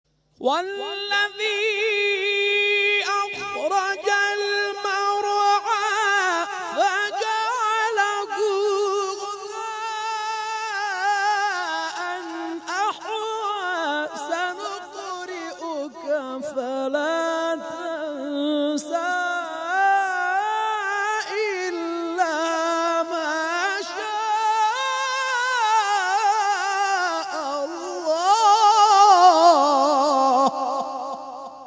شبکه اجتماعی: فرازهای صوتی از تلاوت قاریان ممتاز کشور را می‌شنوید.